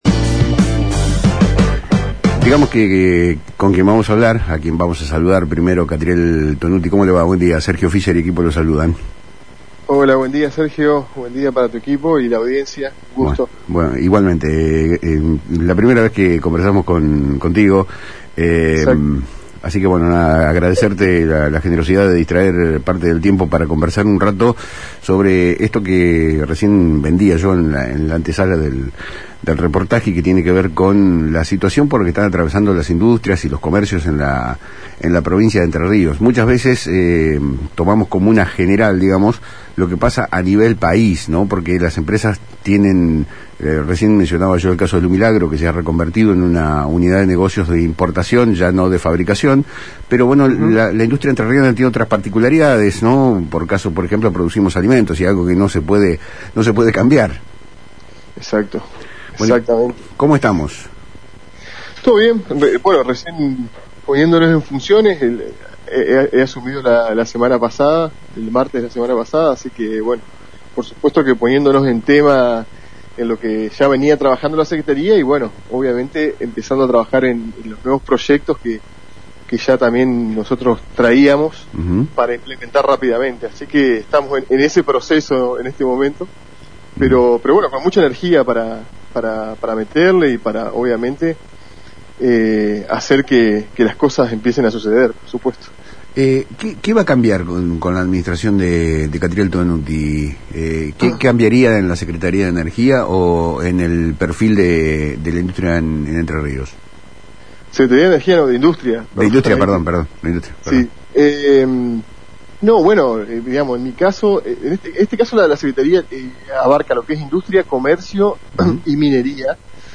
El flamante Secretario de Estado de industria, comercio y minería, Catriel Tonutti, dialogó con Palabras Cruzadas en FM Litoral sobre los objetivos de gestión que prevé llevar adelante.